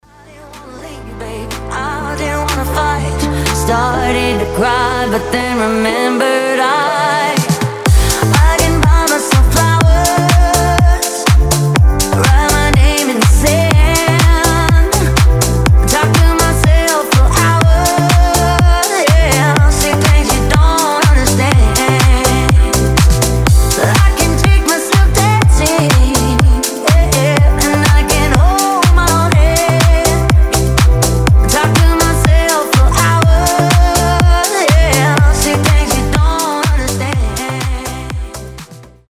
танцевальные
ремиксы